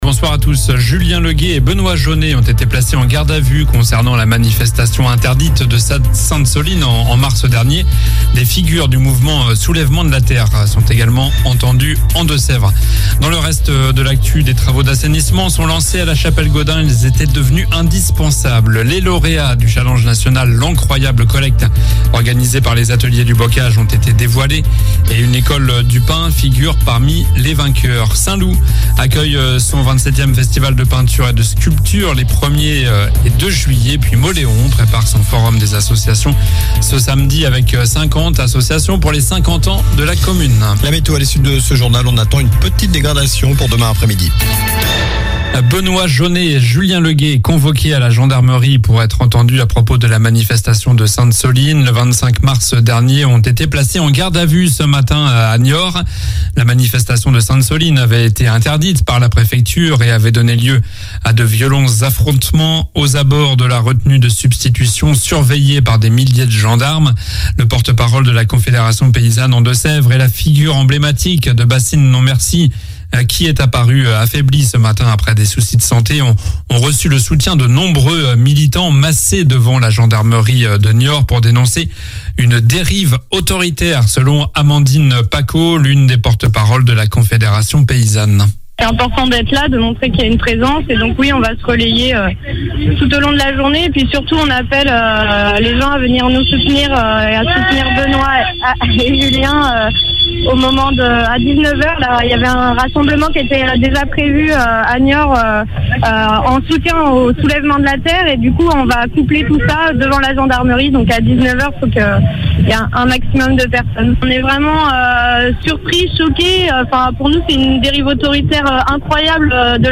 Journal du mercredi 28 juin (soir)